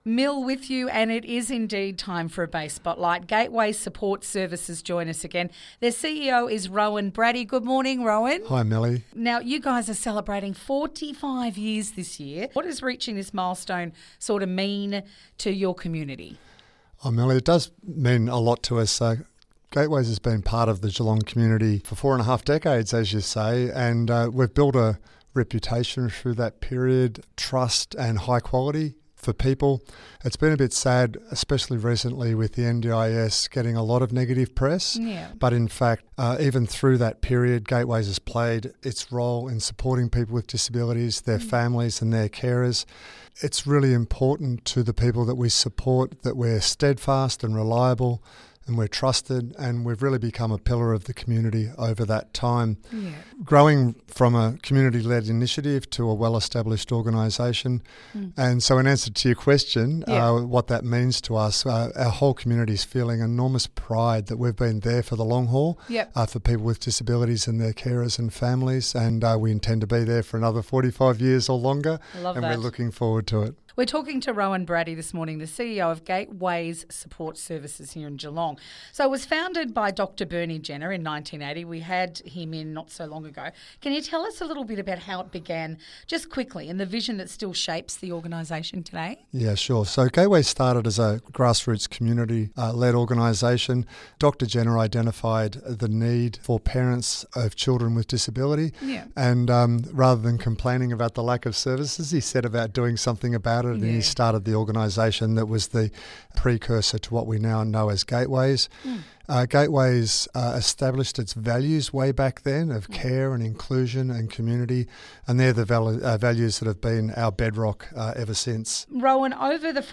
Bay Fm Interview